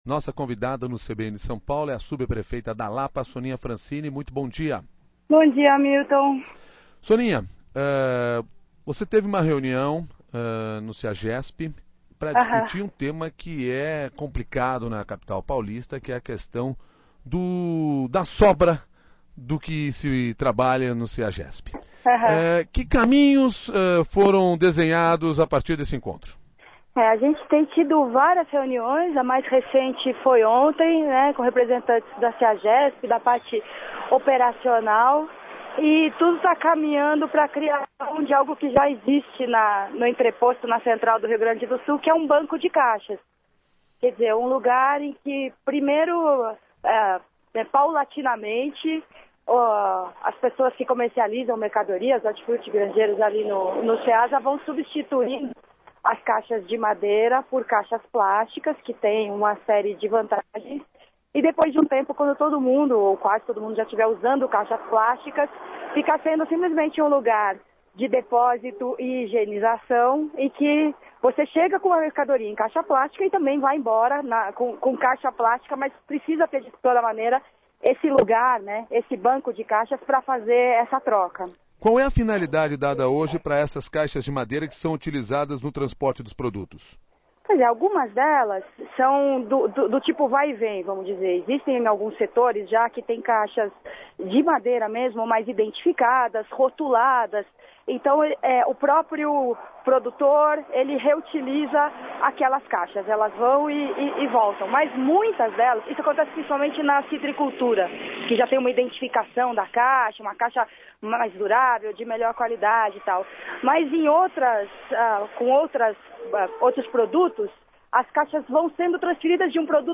Ouça a entrevista de Soninha Francine, subprefeita da Lapa